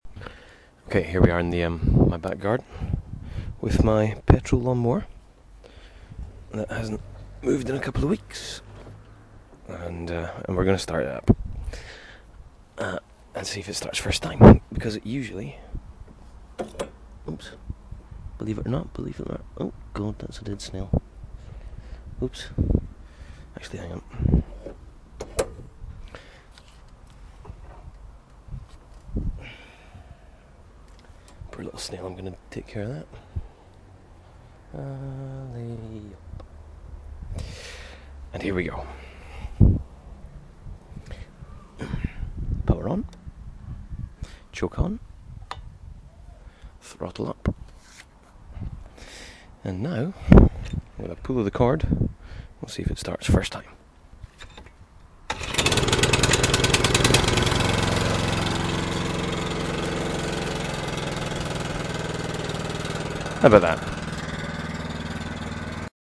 25911-lawnmower-boo.mp3